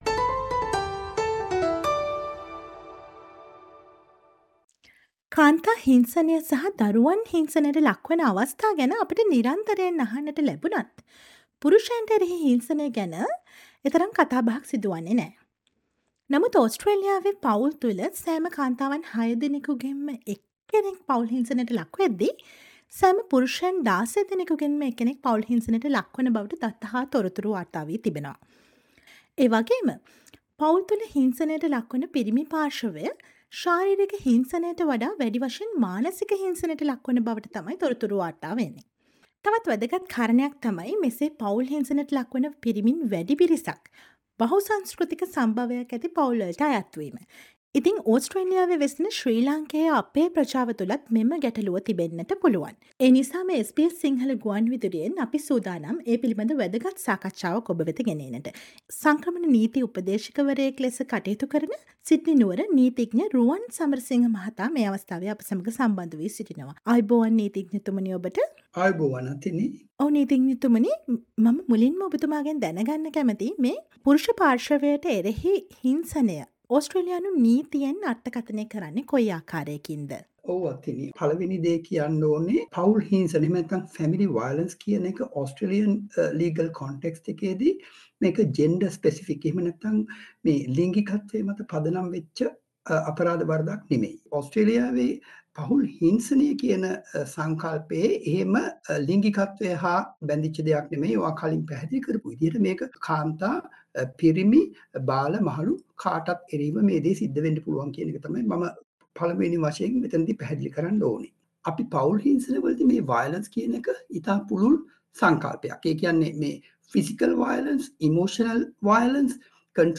සාකච්ඡාවට සවන් දෙන්න ඡායාරූපය මත ඇති speaker සලකුණ මත ක්ලික් කරන්න.